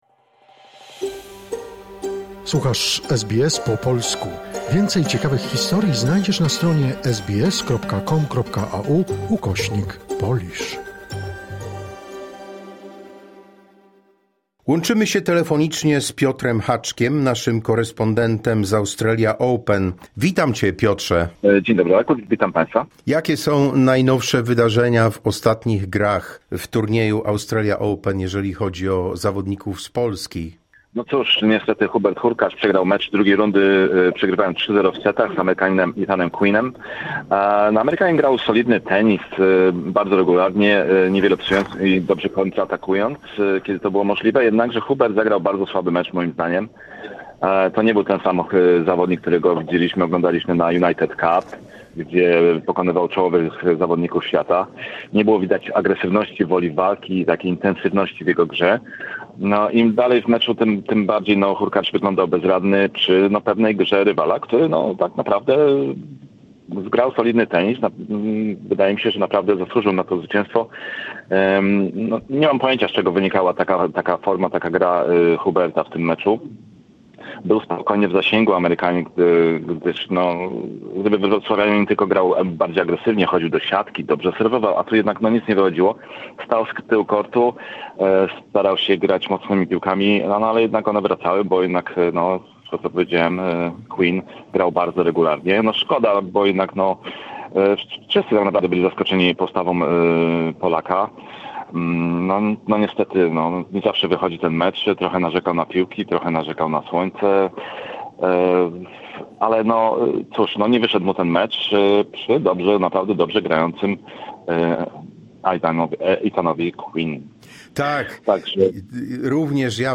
Australian Open 2026 - relacja z kortów w Melbourne